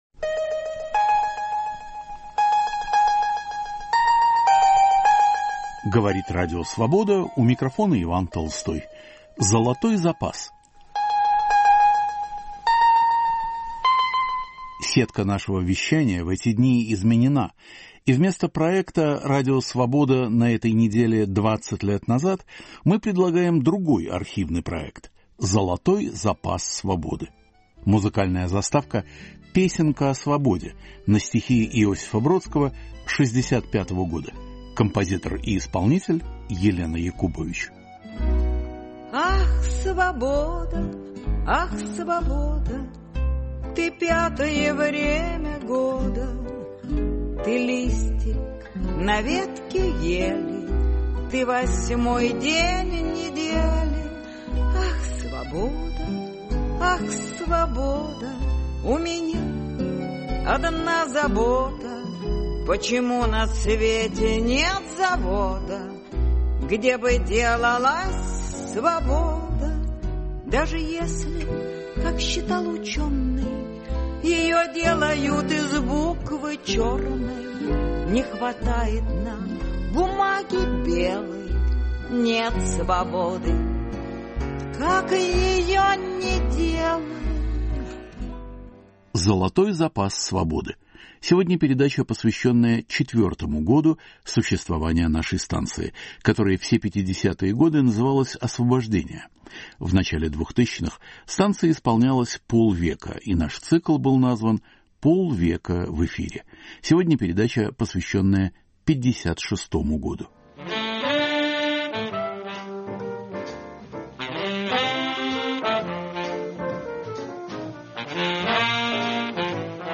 Передача из цикла, посвященного 50-летию Радио Свобода. Год 1956 по архивным передачам: XX съезд, мода на русский язык, голливудский фильм "Война и мир".